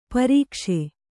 ♪ parīkṣe